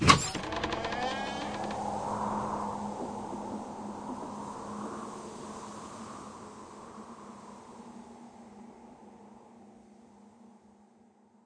WoodenDoorOpen.ogg